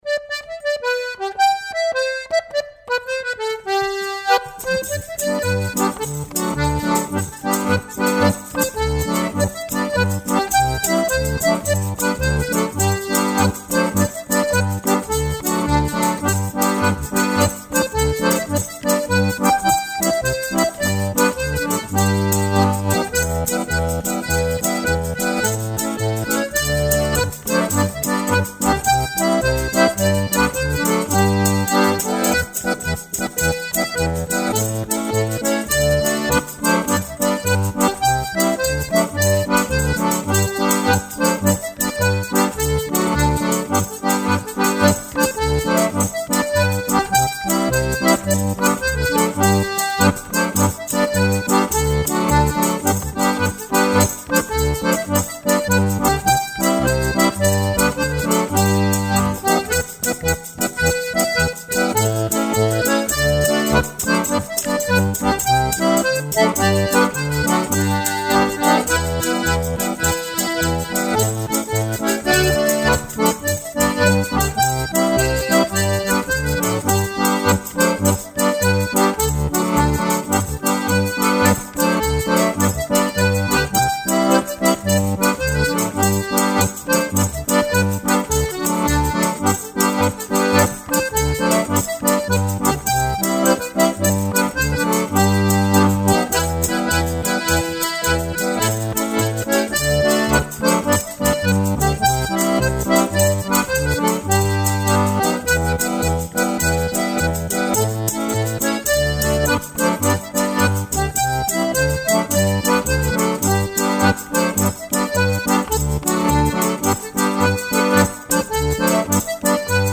Two jigs.